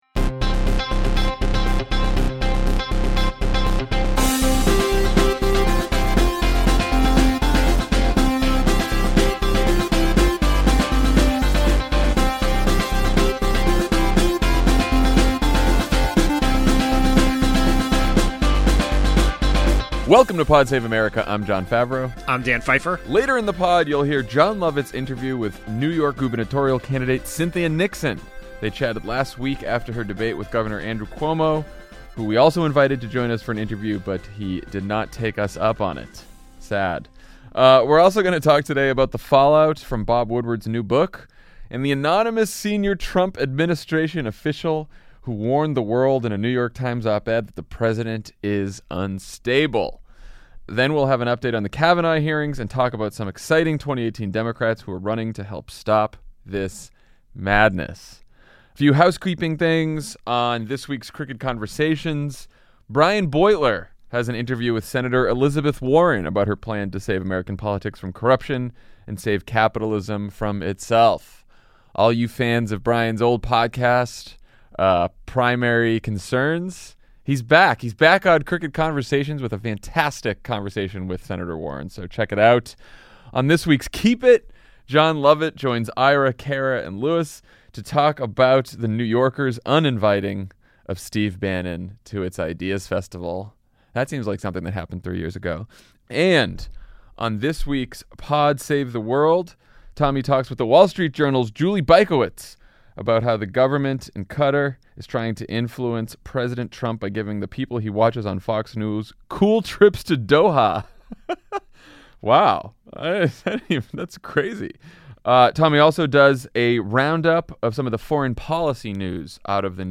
Trump administration officials confirm that the president is unstable in the New York Times and Bob Woodward’s new book, Kavanaugh struggles under tough questioning from Senate Democrats, and we talk about Ayanna Pressley’s primary upset in Massachusetts, and Colin Allred’s tight race for Congress in Texas. Then Jon Lovett interviews New York gubernatorial candidate Cynthia Nixon about her race against Governor Andrew Cuomo, and the future of the Democratic Party.